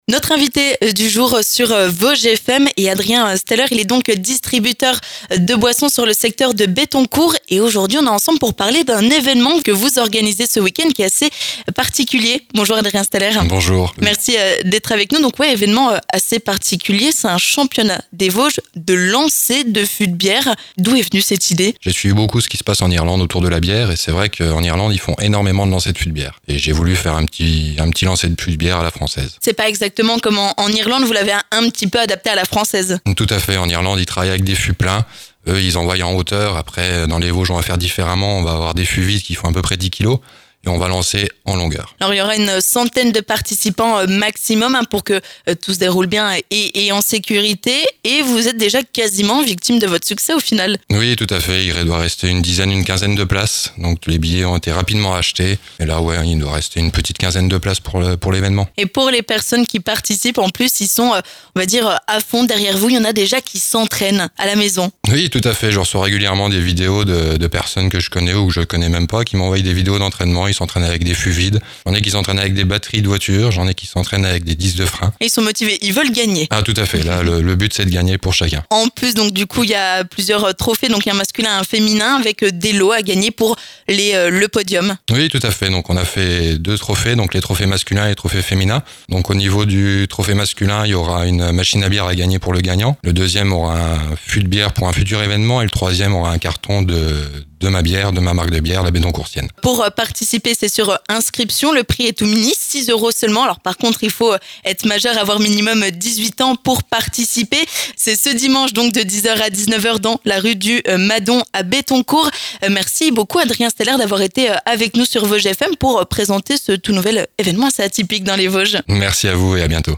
On en parle avec notre invité du jour